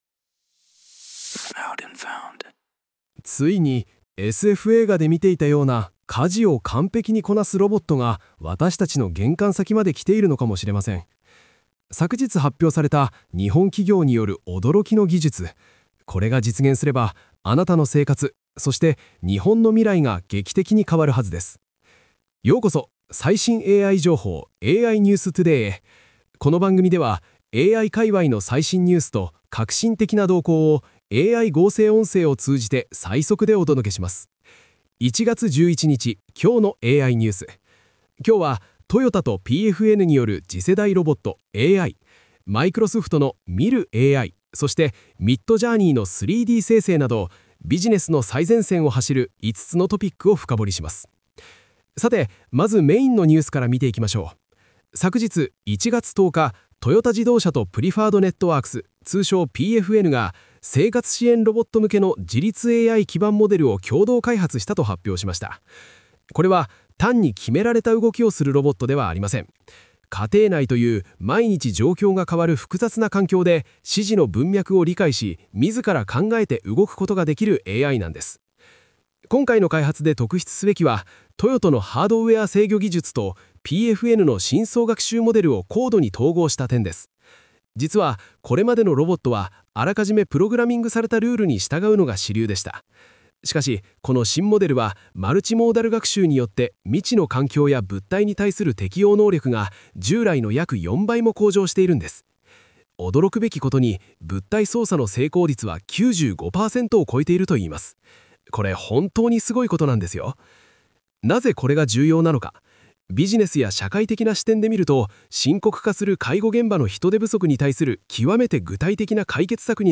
🤖 AI合成音声で最速ニュースをお届け